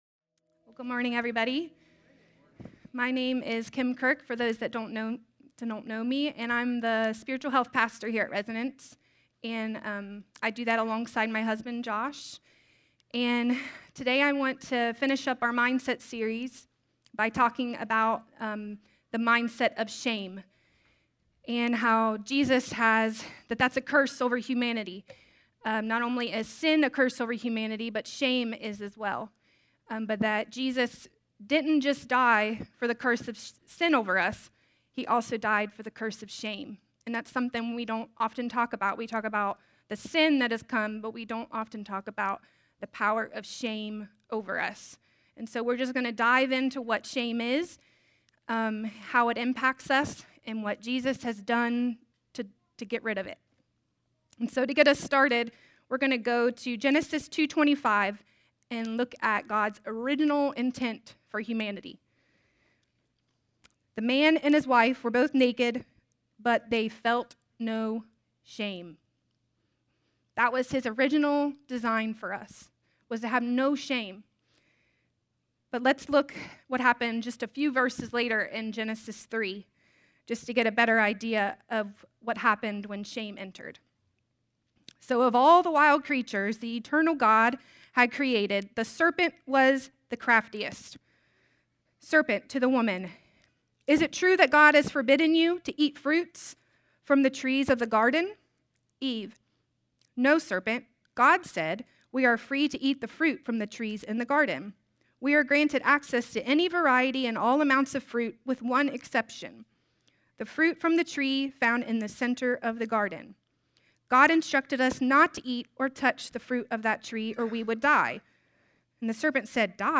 A sermon from the series “(RE)DEFINED.”…